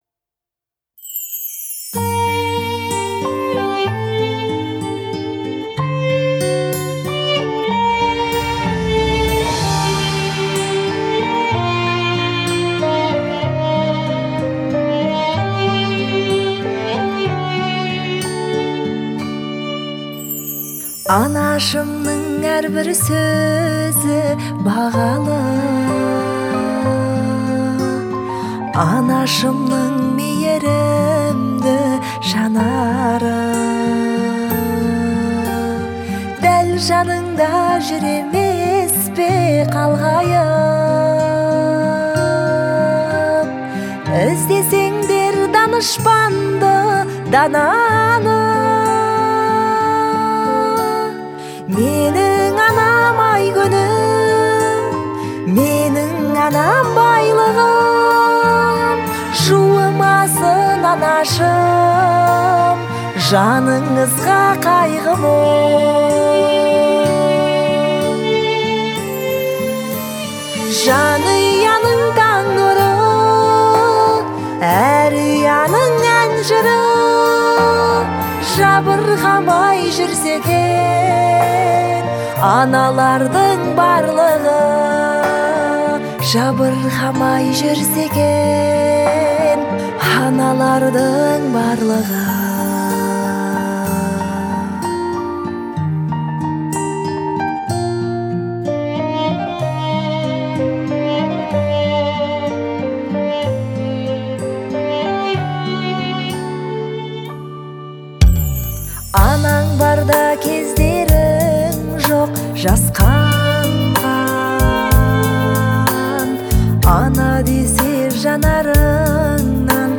выполненная в жанре народной музыки.